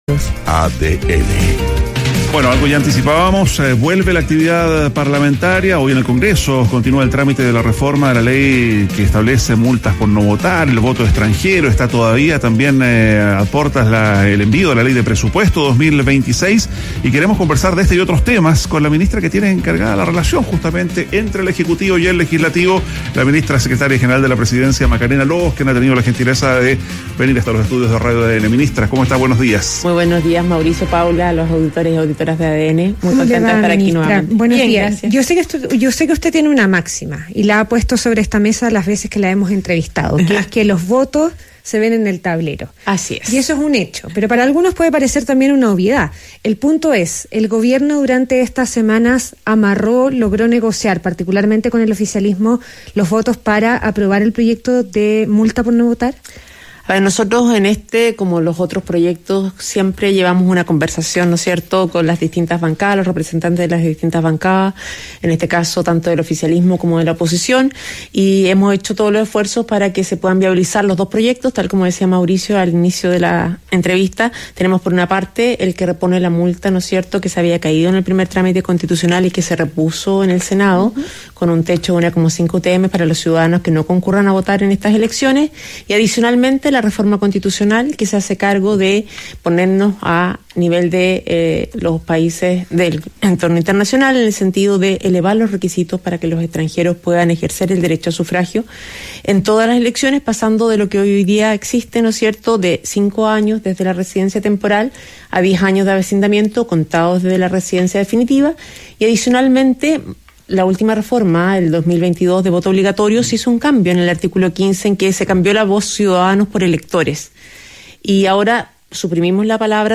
ADN Hoy - Entrevista a Macarena Lobos, ministra secretaria general de la Presidencia